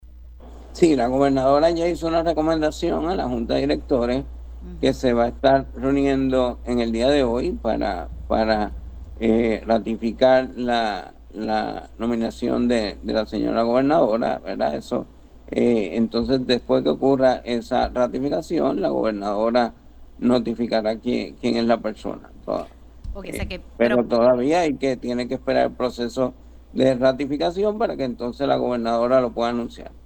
308-VICTOR-RAMOS-SEC-SALUD-JUNTA-HOY-DEBE-RATIFICAR-NOMBRAMIENTO-EN-ASES.mp3